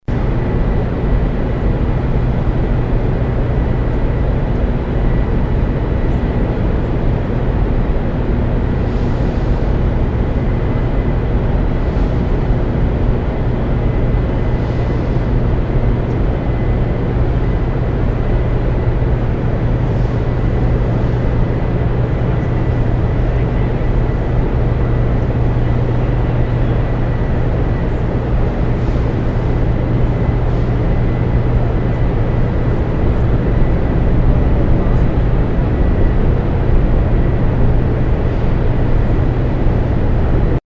Hoover Dam Turbine Generator Acoustics